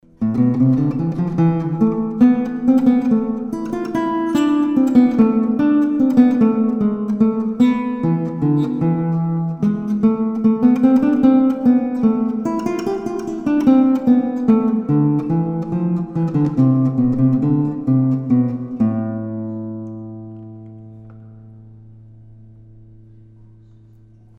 Made in 1880, it is a lauten - a cross between a lute
Six strings and actual worm drive machine heads, not pegs.
done with the pads of the fingers, so no sparkle, I'm afraid.
depth of the instrument is there to hear.